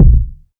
KICK.132.NEPT.wav